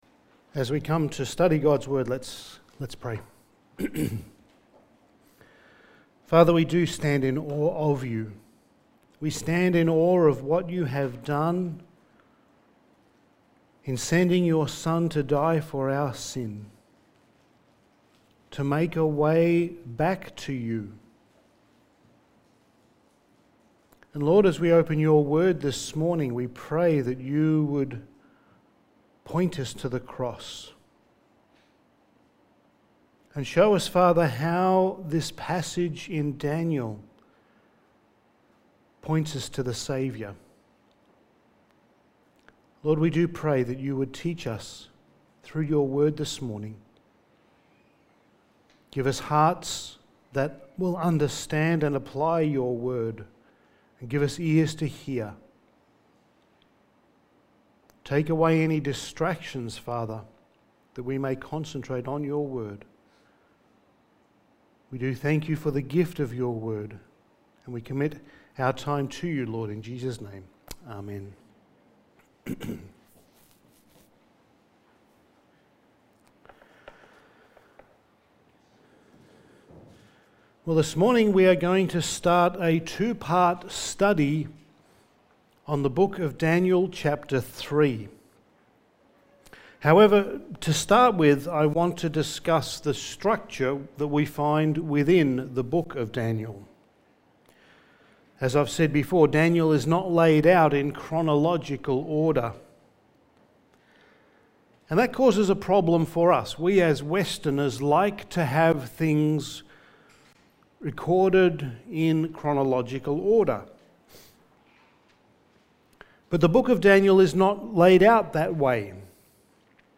Passage: Daniel 3:1-18 Service Type: Sunday Morning